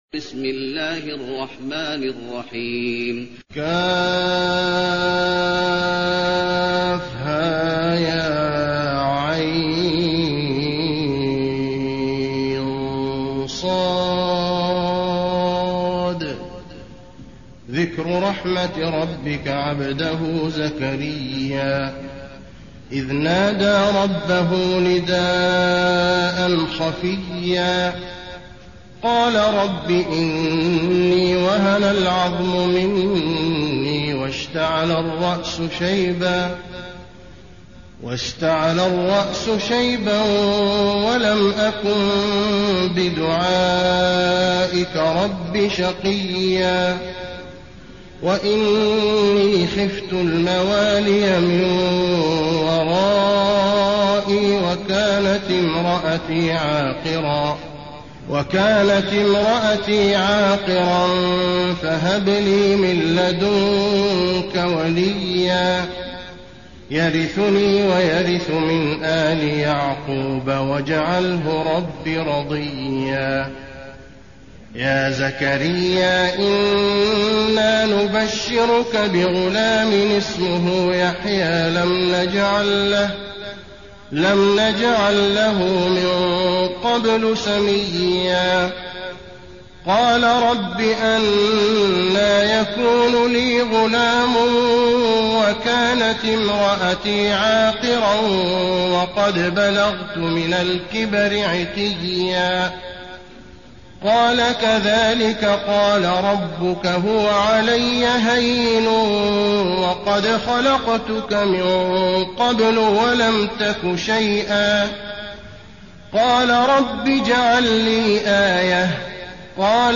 المكان: المسجد النبوي مريم